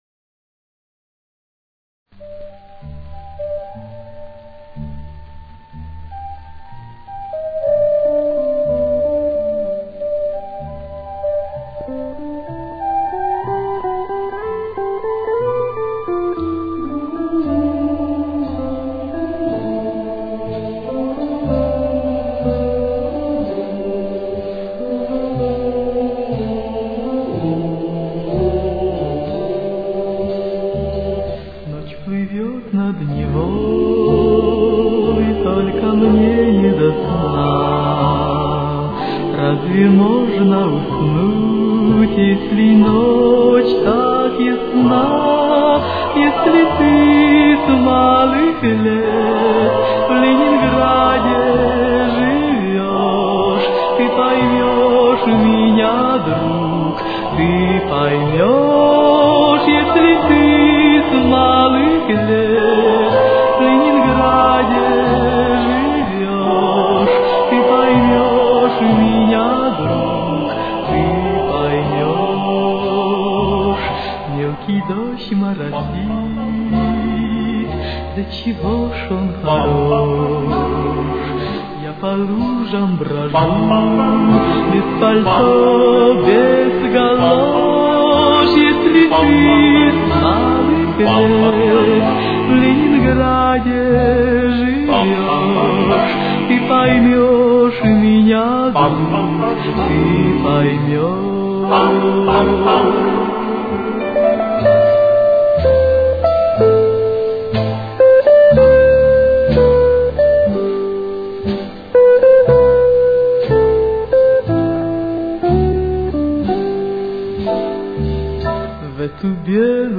с очень низким качеством (16 – 32 кБит/с)